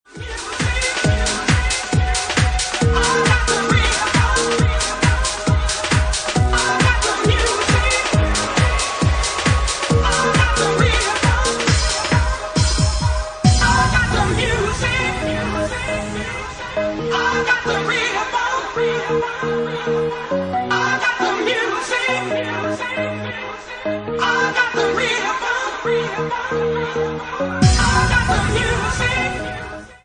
Bassline House at 136 bpm